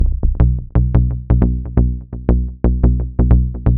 • Techno Berlin Bass Delayed.wav
Techno_Berlin_Bass_Delayed__Q9P.wav